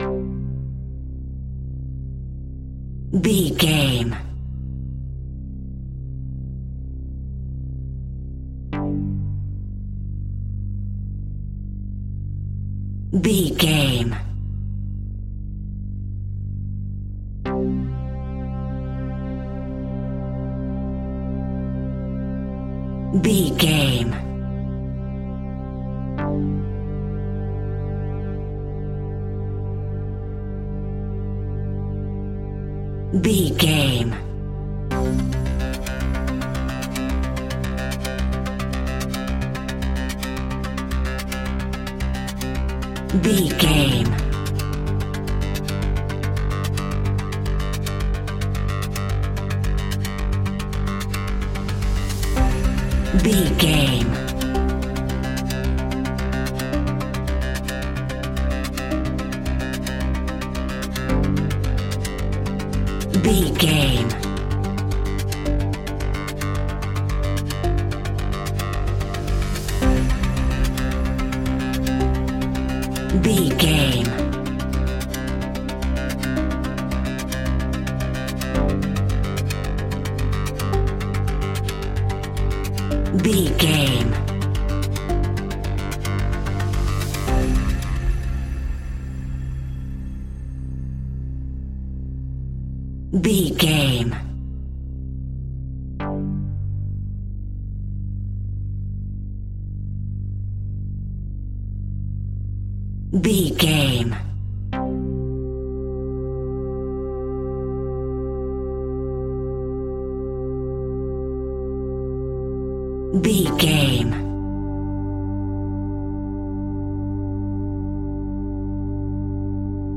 In-crescendo
Thriller
Aeolian/Minor
scary
ominous
dark
haunting
eerie
creepy
instrumentals
horror music
Horror Pads
horror piano
Horror Synths